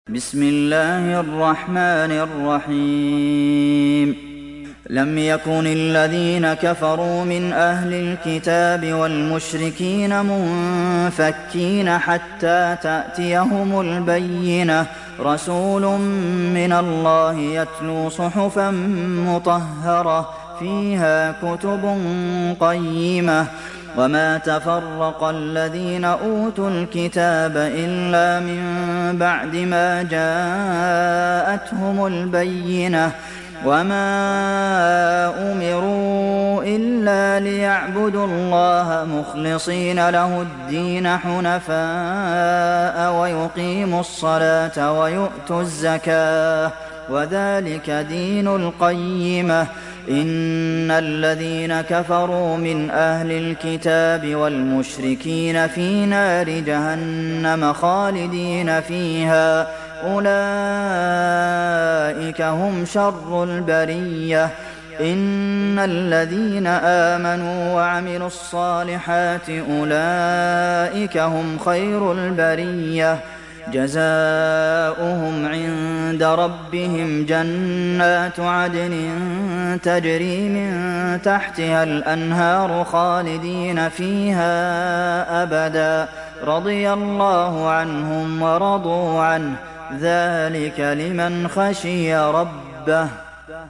دانلود سوره البينه mp3 عبد المحسن القاسم روایت حفص از عاصم, قرآن را دانلود کنید و گوش کن mp3 ، لینک مستقیم کامل